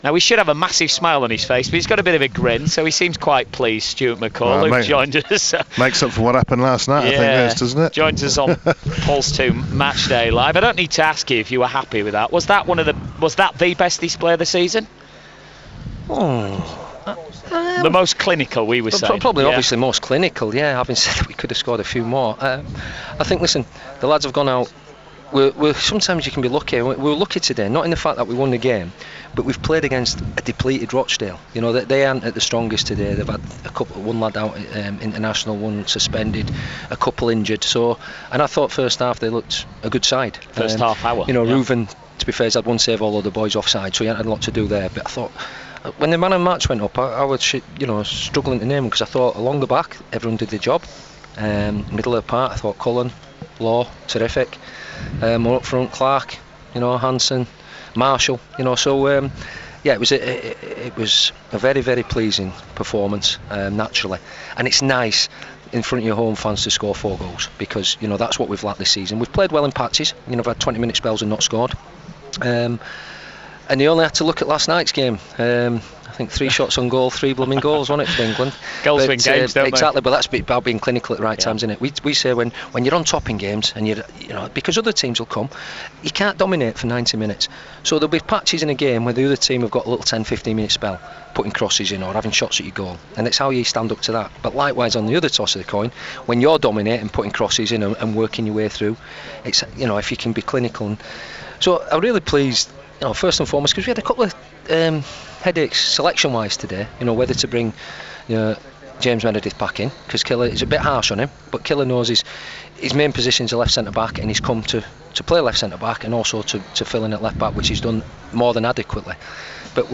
Stuart McCall Post Match Interview vs Rochdale 4-0